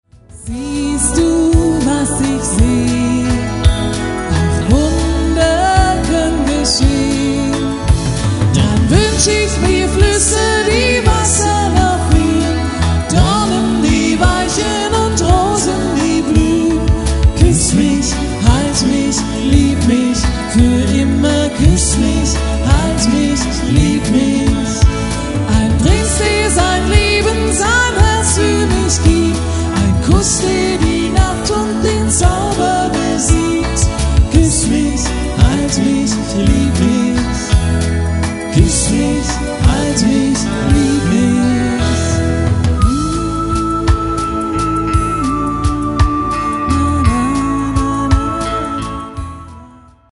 Die folgenden Tracks sind Live-Mitschnitte von 2013 - 2018
mit Zoom H2 Handy-Recorder vom Mix Dynacord CMS 2200,